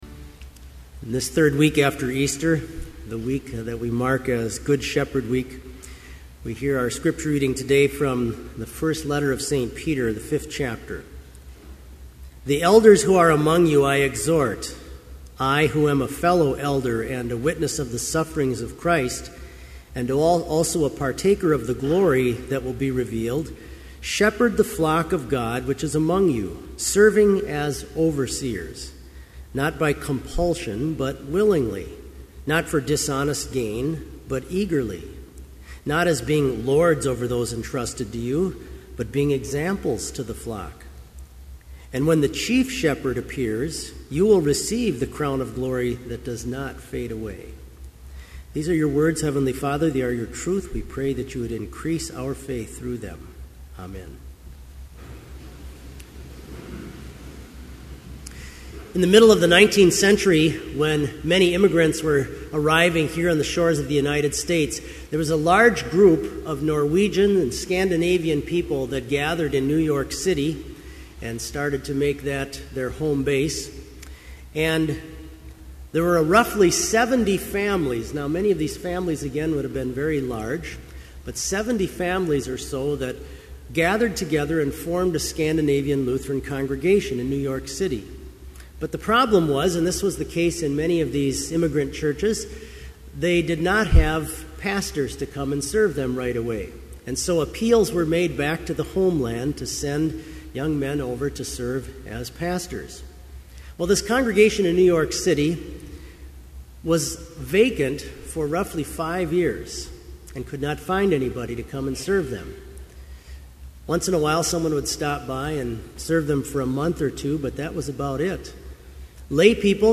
Complete service audio for Chapel - May 11, 2011